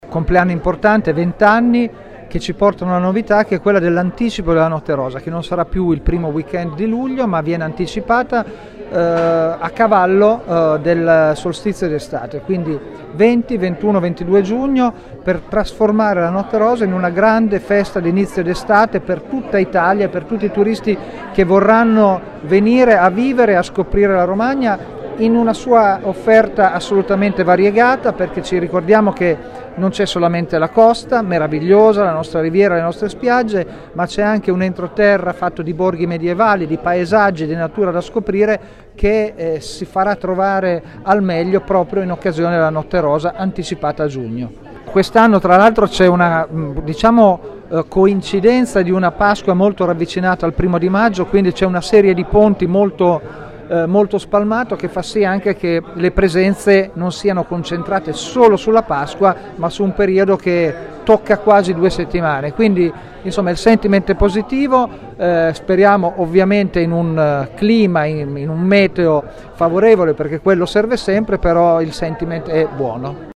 Ascolta l’intervista a Jamil Sadegholvaad, sindaco di Rimini e presidente di Visit Romagna: